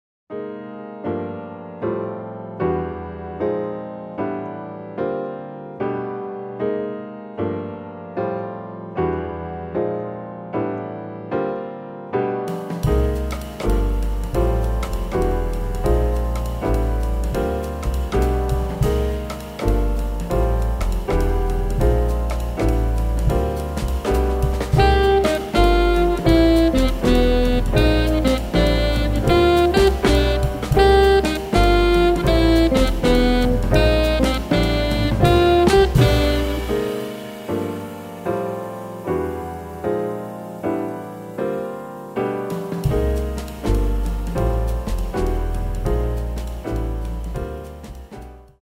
Каталог -> Джаз и около -> Тихие эмоции
гитара
саксофон
фортепиано
контрабас
барабаны